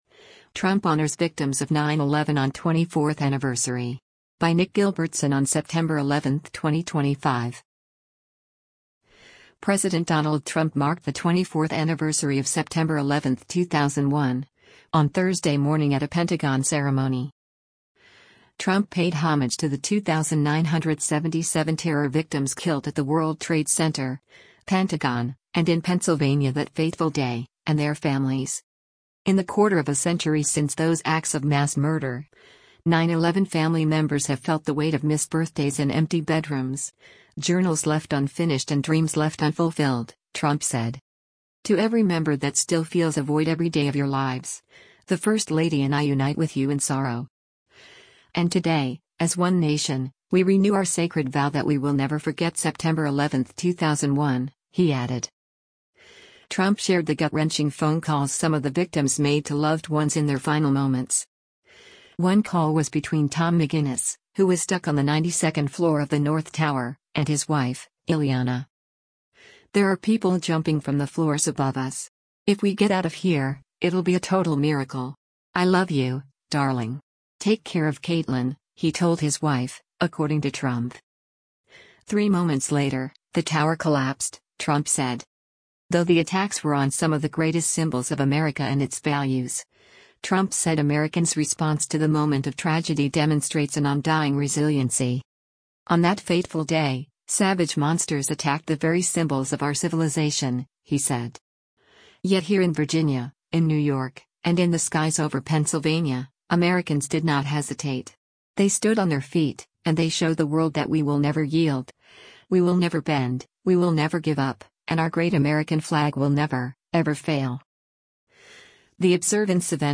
President Donald Trump marked the 24th anniversary of September 11, 2001, on Thursday morning at a Pentagon ceremony.